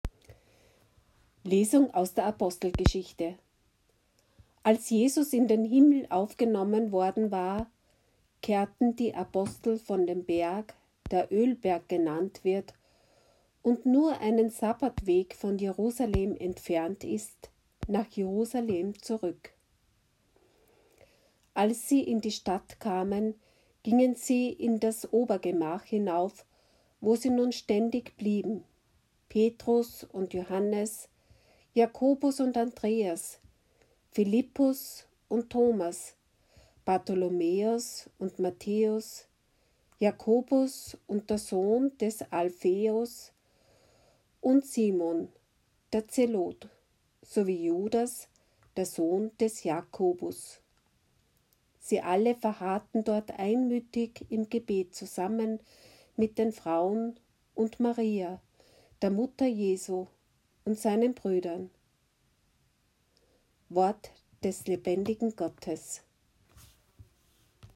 Audiolesung: 1. Lesung Apg 1,12-14
Lesung_7__Sonntag_der_Osterzeit_m4a